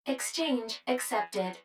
153_Exchange_Accepted.wav